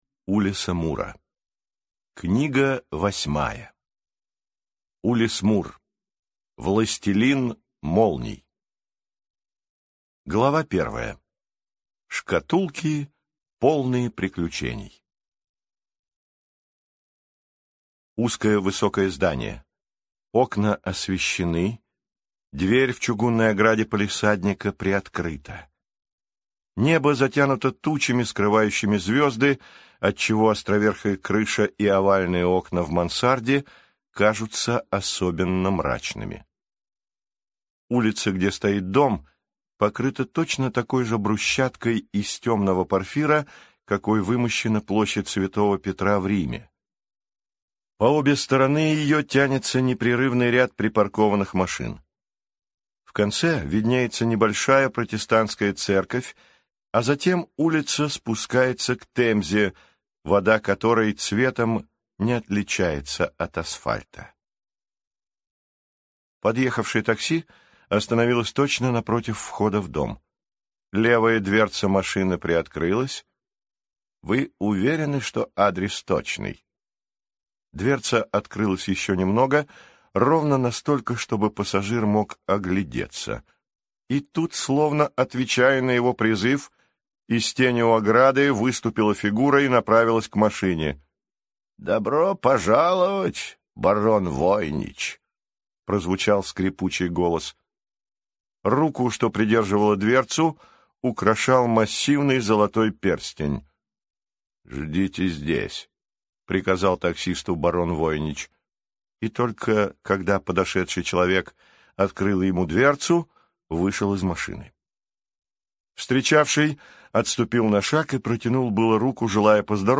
Аудиокнига Властелин молний | Библиотека аудиокниг
Прослушать и бесплатно скачать фрагмент аудиокниги